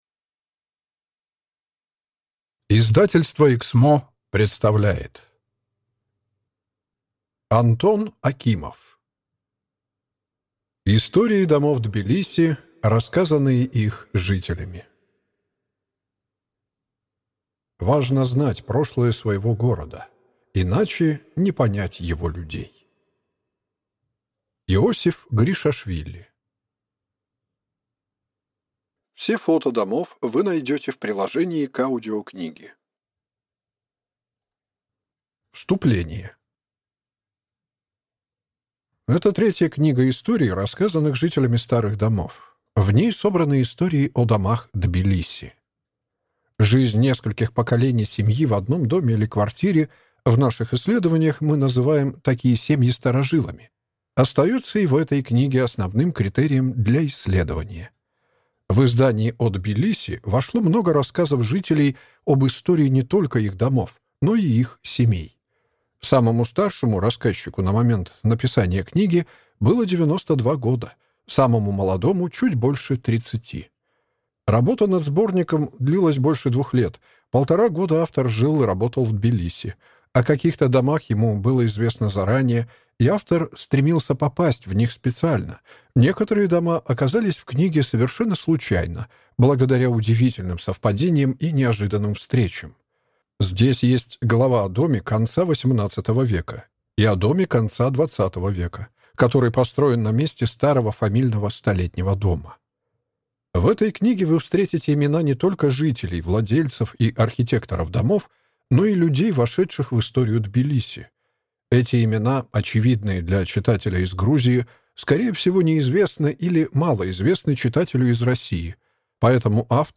Аудиокнига Истории домов Тбилиси, рассказанные их жителями | Библиотека аудиокниг